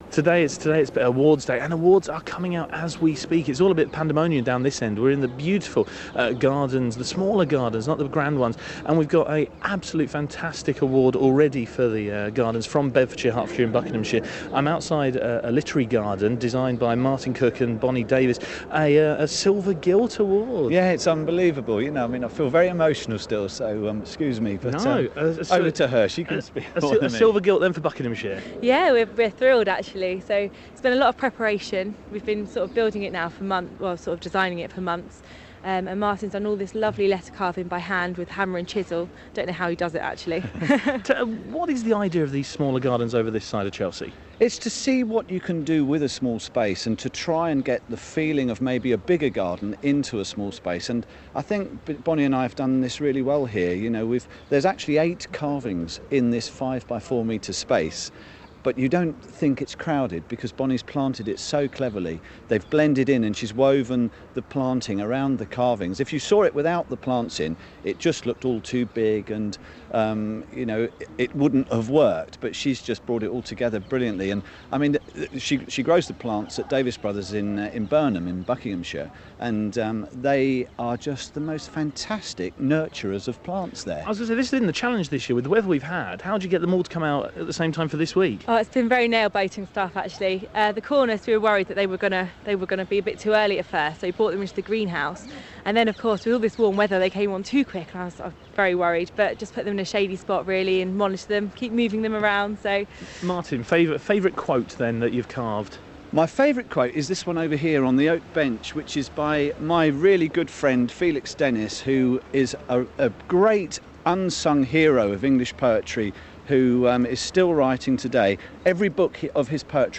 live from The Chelsea Flower Show